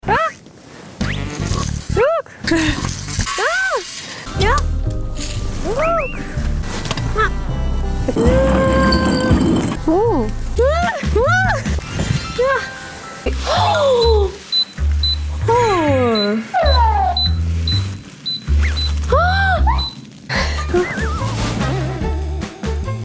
the cutest sounds I've ever heard 🥹